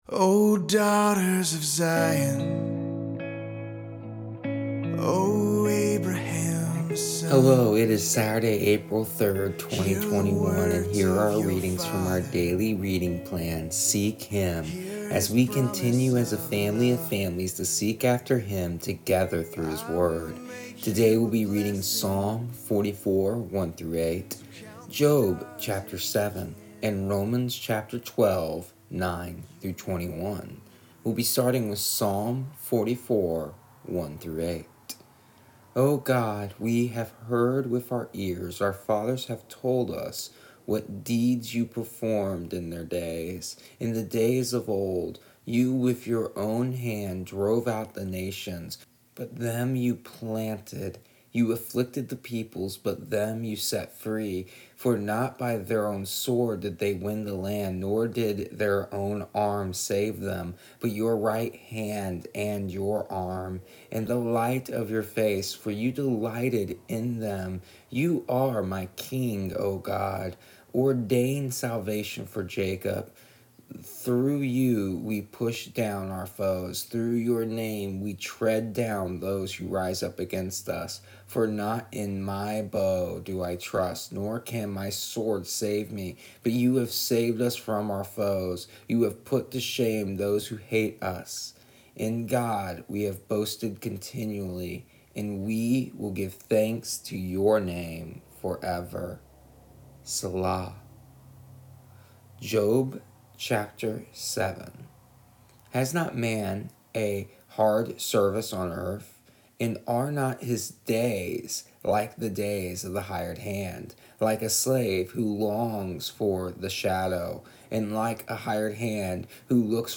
Here is the audio version of our daily readings from our daily reading plan Seek Him for April 3rd, 2021.